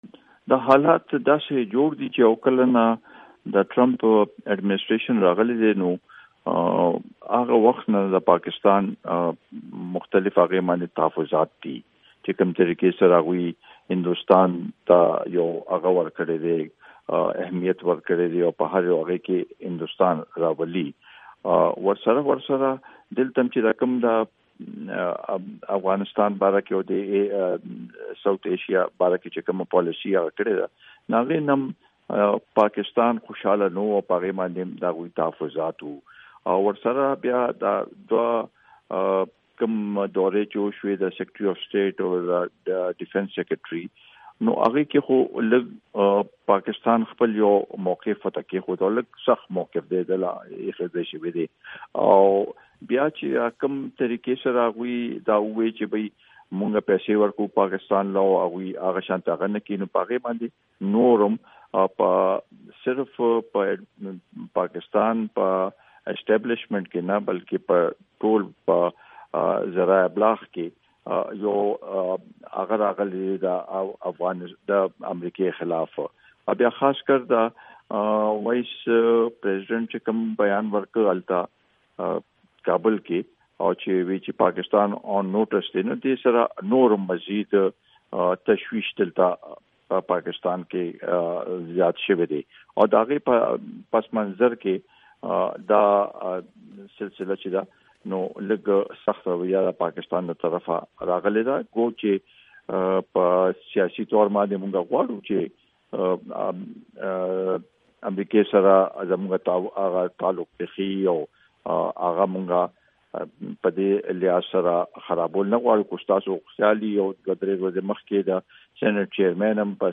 د آفتاب احمد خان شېرپاو سره مرکه